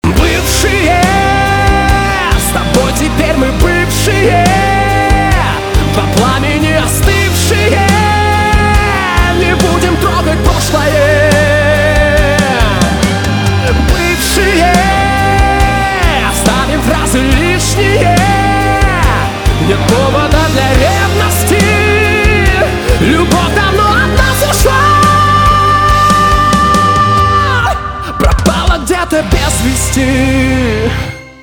поп
грустные , печальные
чувственные
барабаны , гитара , скрипка